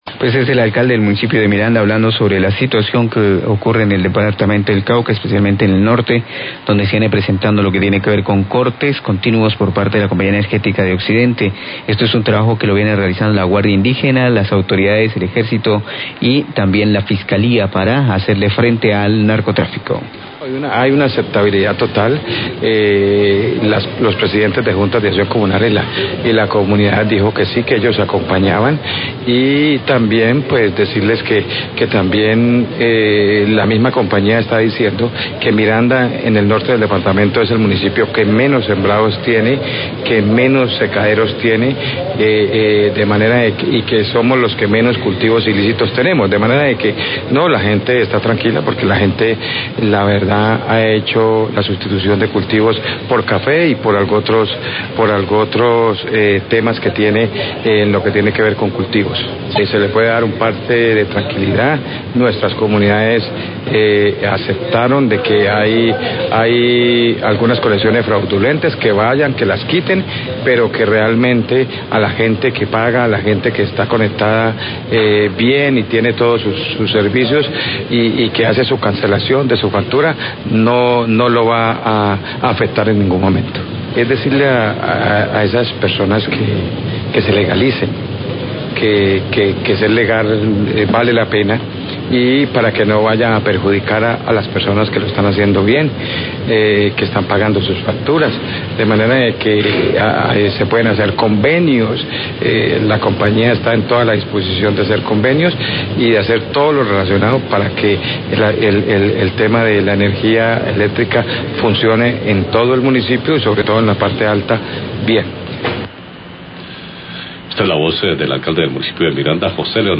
Radio
El Alcalde de Miranda, José Valencia, se refiere a los cortes del servicio de energía por parte de la Compañía Energética en conjunto con la guardia indígena, autoridades, Ejército y Fiscalía para hacerle frente a los invernaderos de marihuana. La comunidad acompaña y acepta el proceso de corte de conexiones fraudulentas.